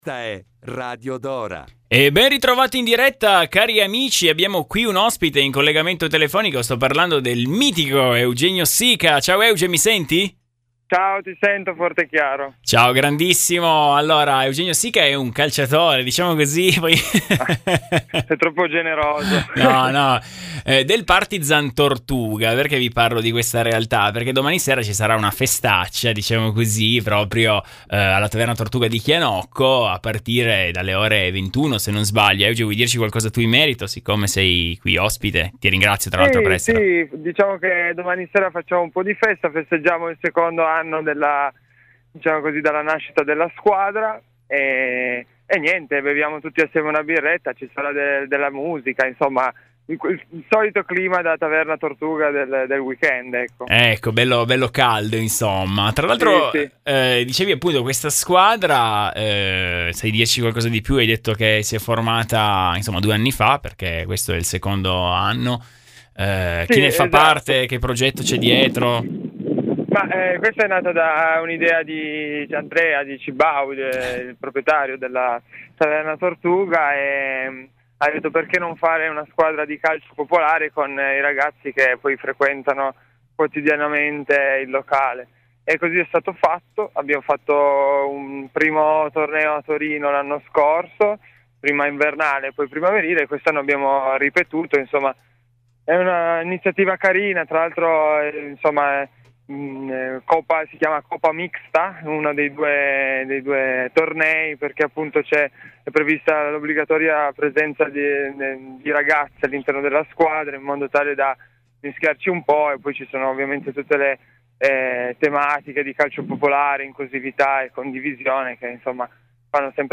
Partizan Tortuga Night - Intervista